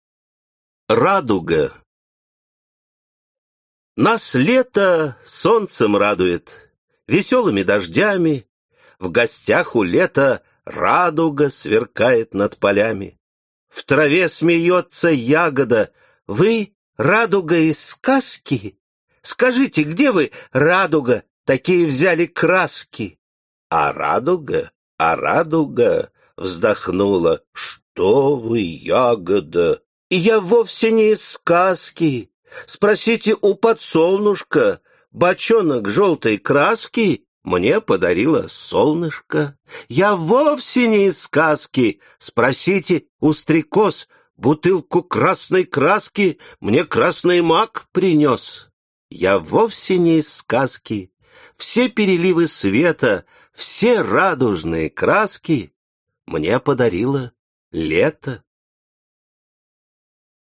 Aудиокнига Ох и Ах и другие Автор Людмила Зубкова Читает аудиокнигу Лев Дуров.